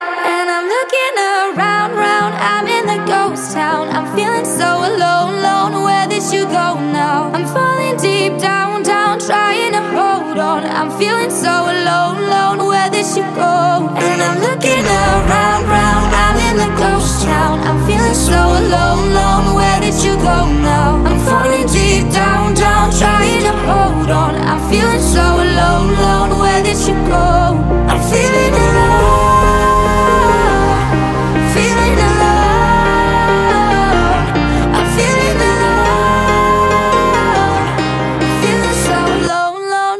танцевальные , спокойные , дуэт
нарастающие , красивый вокал